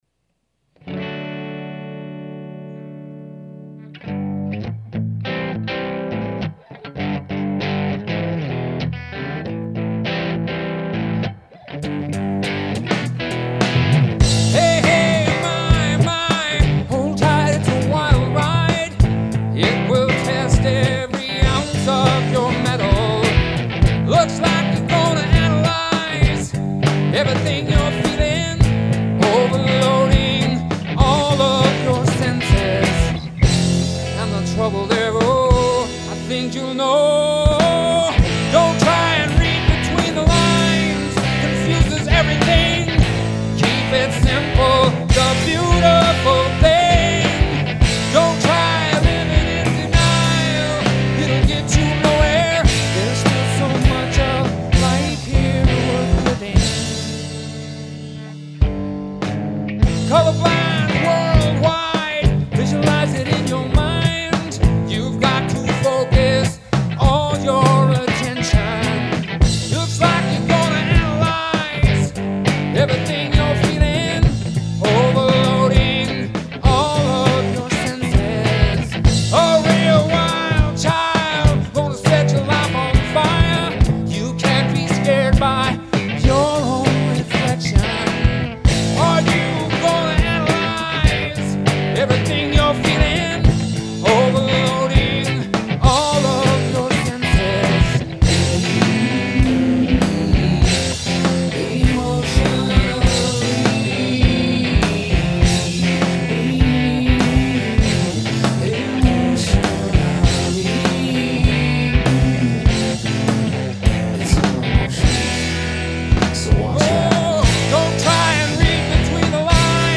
Most of the songs I've recorded start out as rough drafts just to get the idea down on tape, then I add some more tracks, and mix it, and... that's all I end up with is that rough draft.
Recorded using Sony ACID Pro in 2002 (or whatever the software was called at the time)